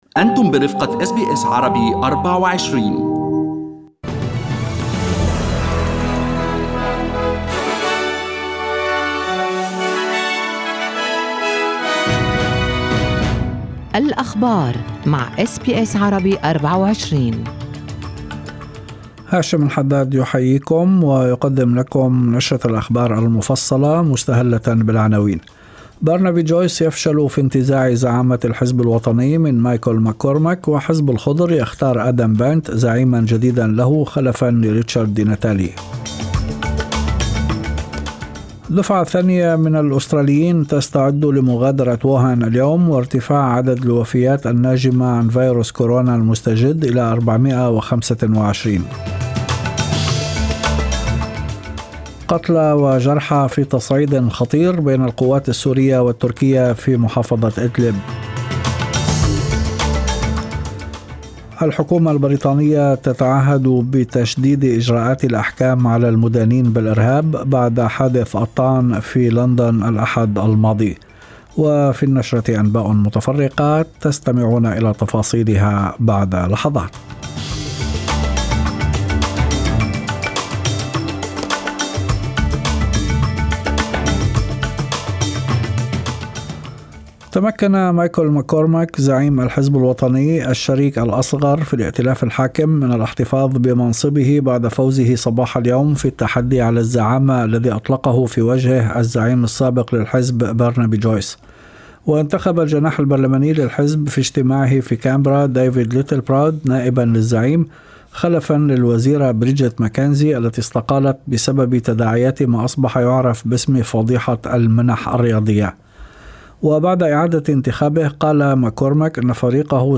نشرة أخبار المساء 04/02/2020
Arabic News Bulletin Source: SBS Arabic24